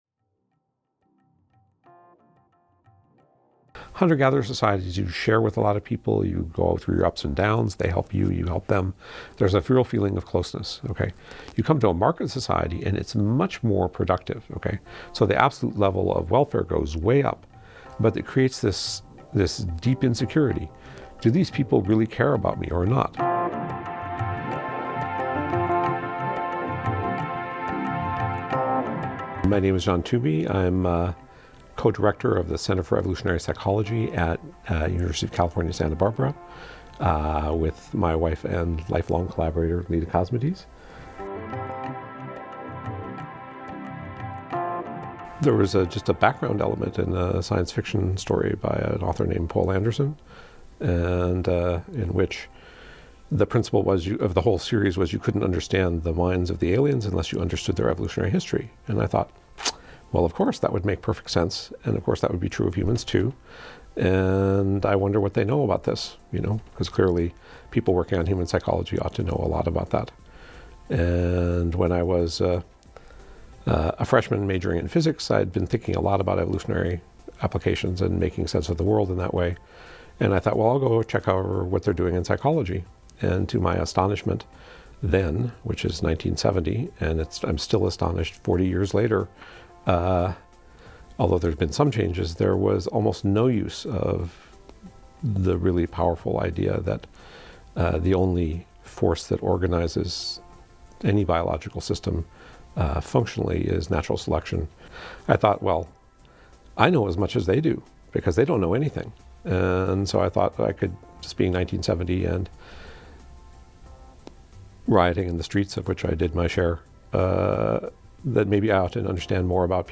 Stone Age Minds: A conversation with evolutionary psychologists Leda Cosmides and John Tooby (extended version)